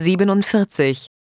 ich habe mal Sprachausgaben für eine Sekunde erstellt sie Anhang .